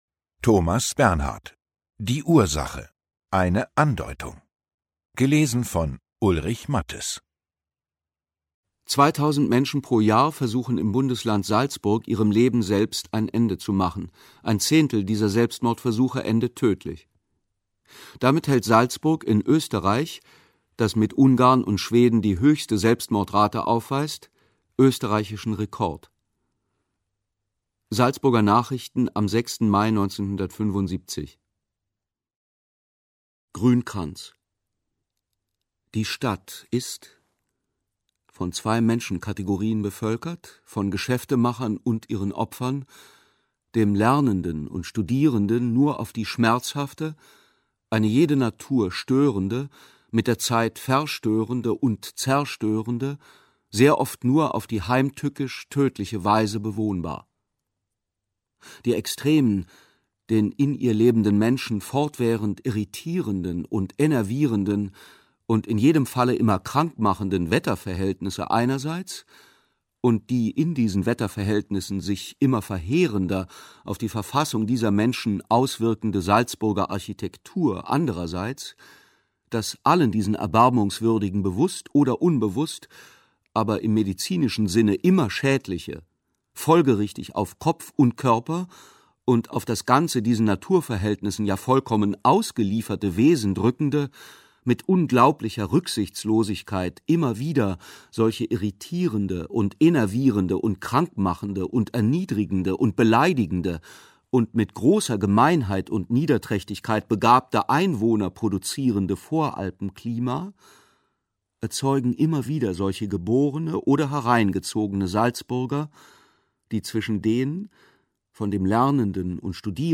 Ungekürzte Lesung mit Ulrich Matthes (1 mp3-CD)
Ulrich Matthes (Sprecher)